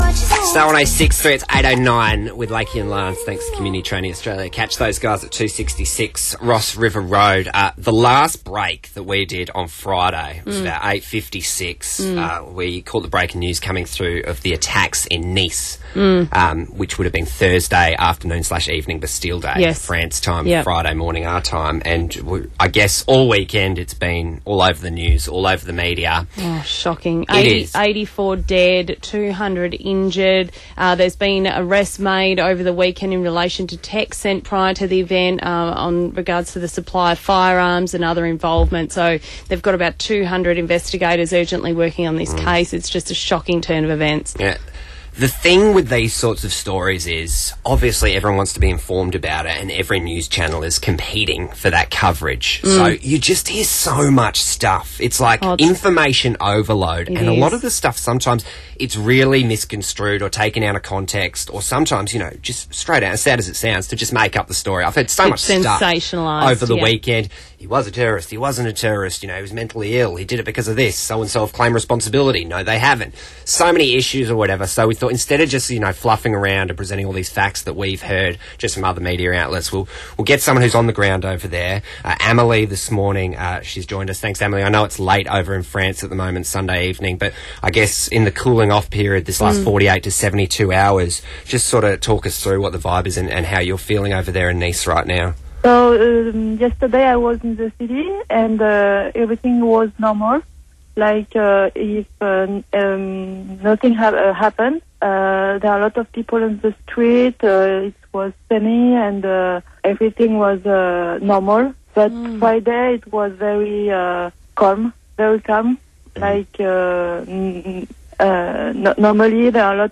chat to Nice Resident